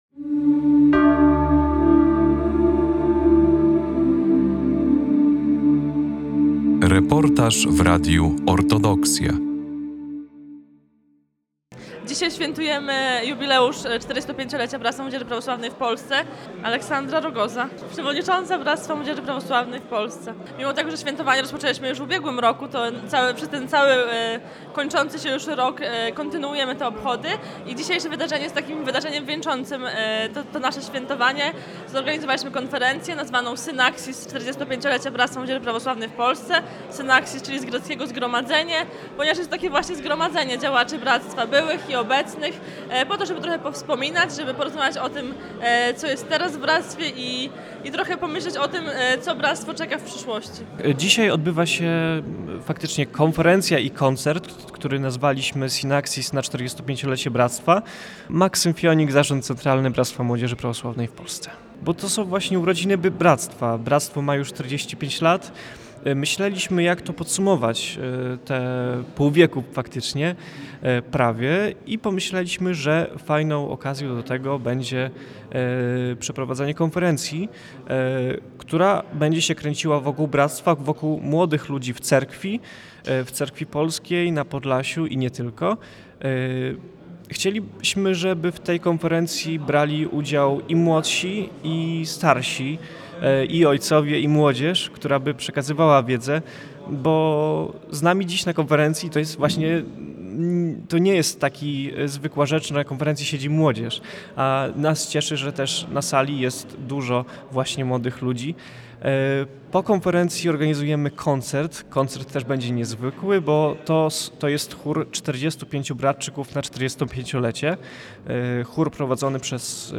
45 lat działania w służbie Bogu i ludziom - relacja z obchodów jubileuszu Bractwa Młodzieży Prawosławnej w Polsce
Zapraszamy Państwa do wysłuchania materiału przygotowanego podczas uroczystości jubileuszu 45-lecia Bractwa Młodzieży Prawosławnej w Polsce. Obchody odbyły się w minioną niedziele w Supraślu i zgromadziły setki młodych ludzi, duchowieństwa oraz osób, które od lat współtworzą działalność Bractwa.